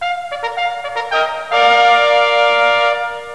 Fanfare1.snd